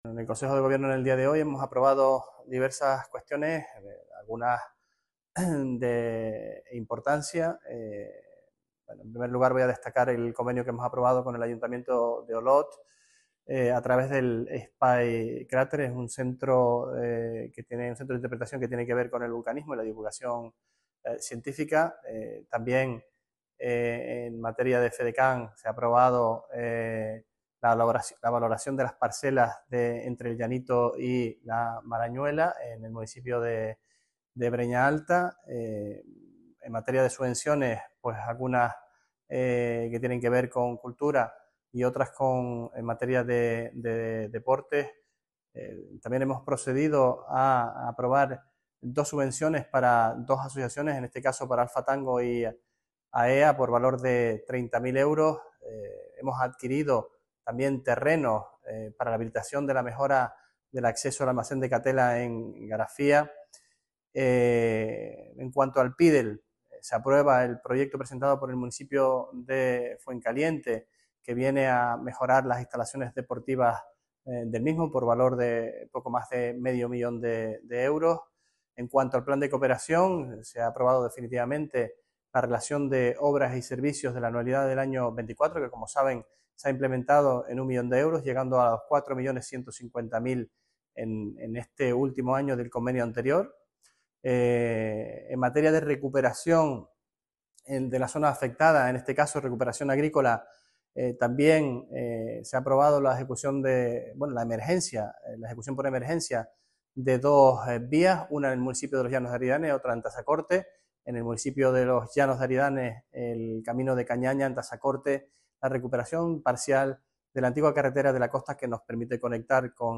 Sergio-Rodriguez-Consejo-de-Gobierno-29-de-noviembre.mp3